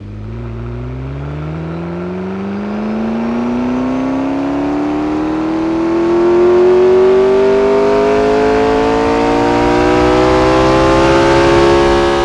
rr3-assets/files/.depot/audio/Vehicles/ttv8_01/ttv8_01_Accel.wav
ttv8_01_Accel.wav